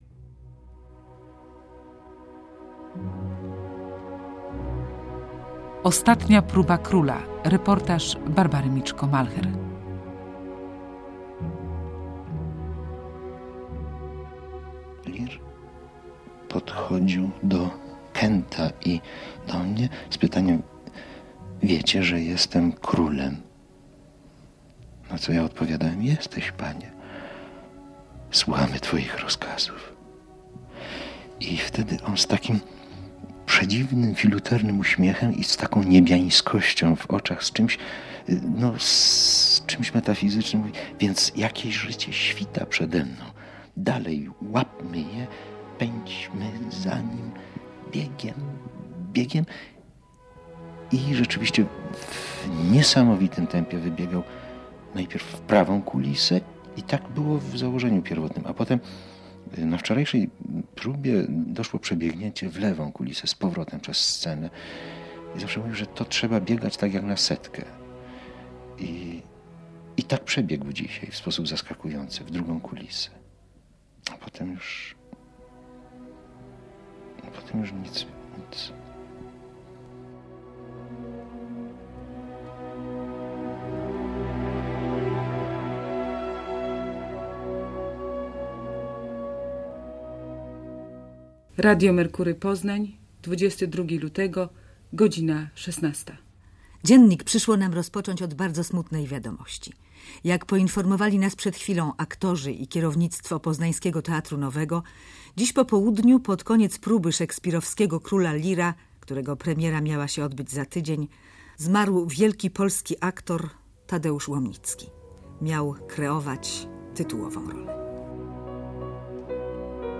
Ostatnia próba króla - reportaż